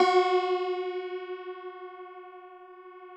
53r-pno12-F2.wav